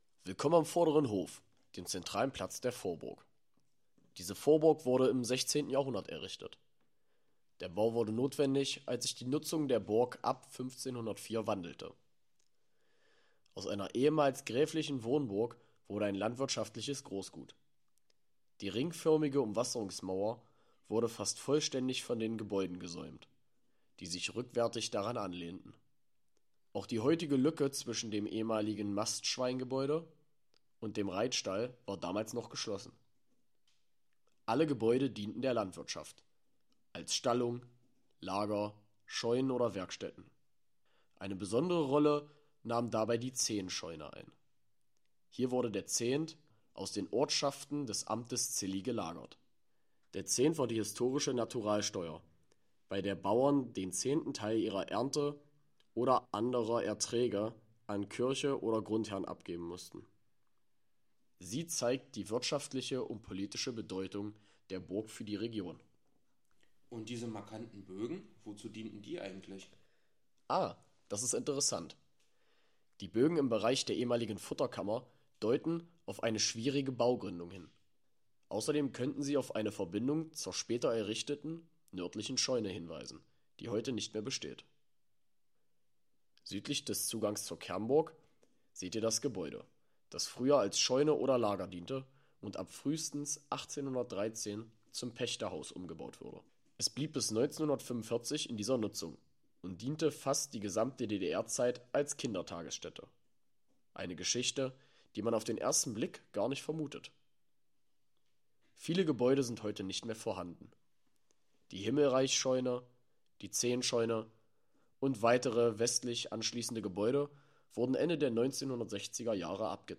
Diese Audiotour begleitet Sie durch Geschichte und Besonderheiten der Anlage.